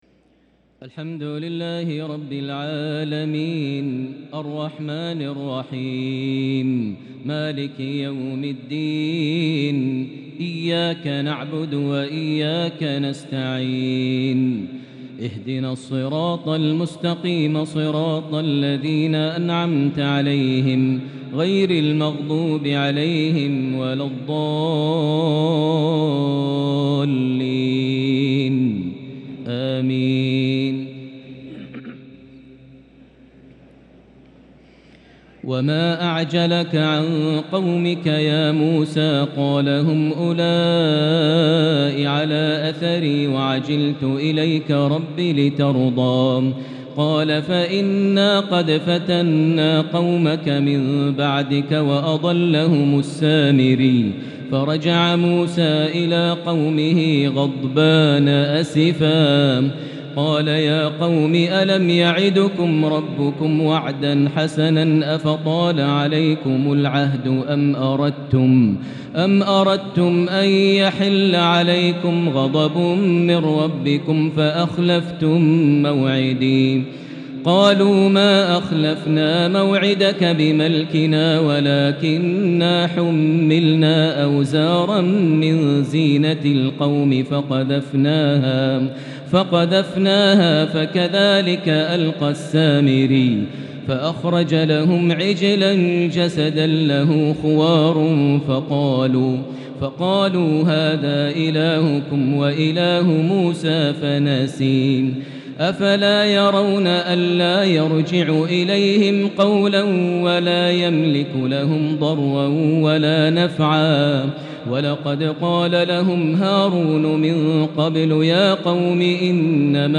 تهجد ليلة 21 رمضان 1443هـ| سورة طه (83) سورة الأنبياء (100) | Tahajjud 21st night Ramadan 1443H -Surah Taha 83+ Surah Al-Anbiya 100 > تراويح الحرم المكي عام 1443 🕋 > التراويح - تلاوات الحرمين